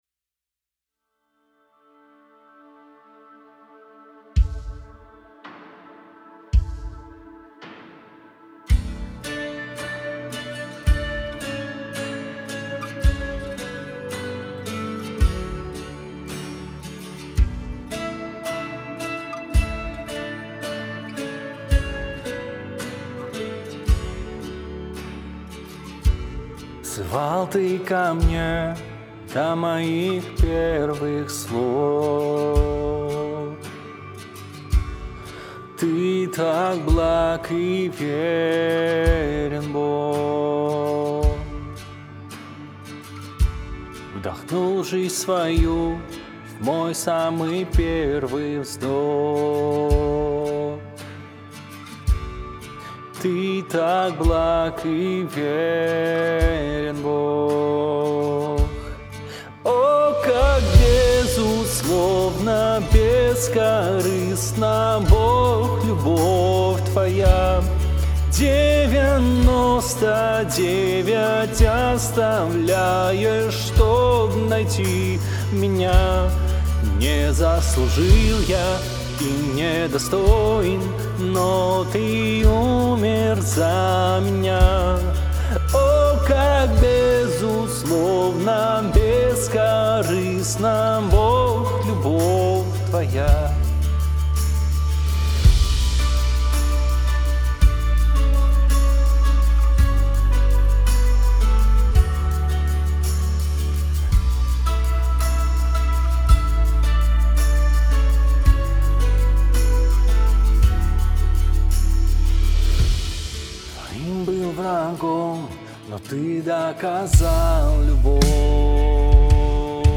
160 просмотров 198 прослушиваний 7 скачиваний BPM: 166